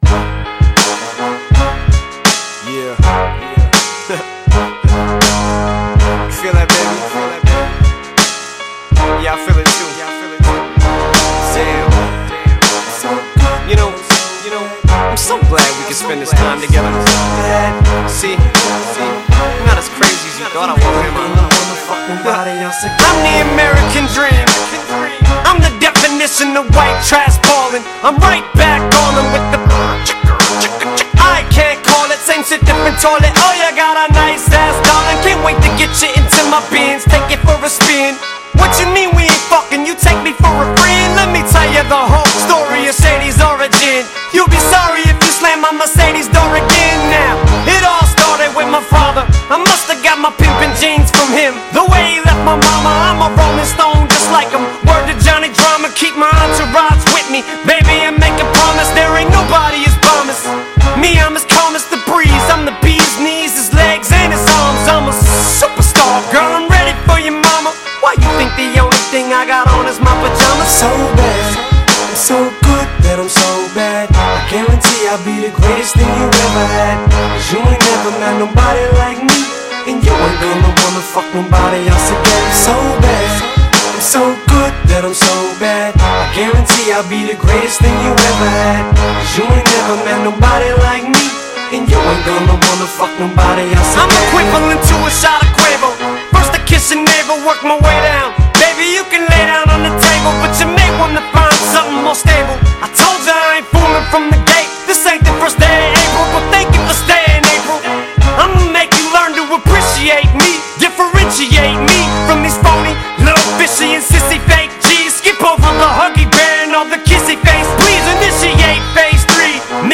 Hip Hop